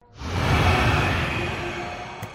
fog.mp3